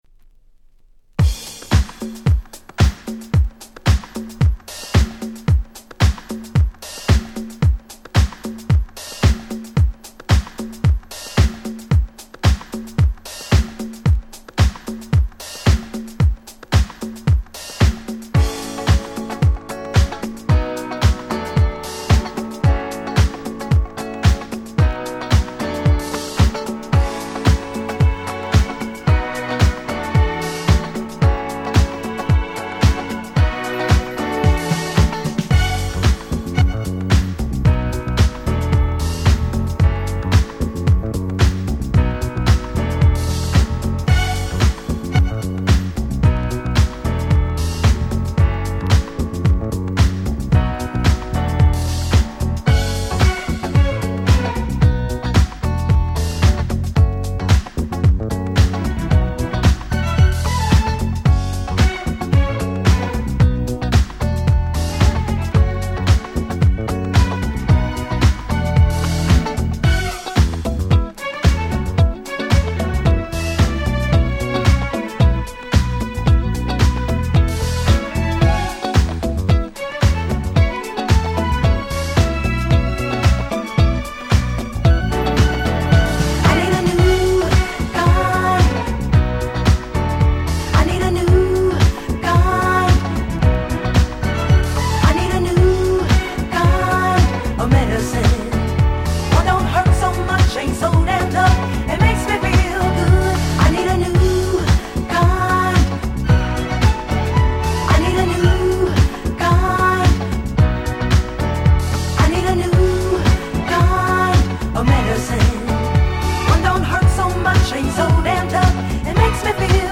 ※試聴ファイルは別の盤から録音してございます。
98' Nice R&B !!